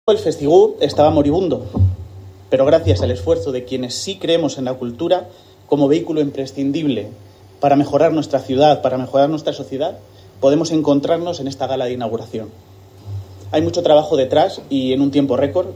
Declaraciones del alcalde Miguel Óscar Aparicio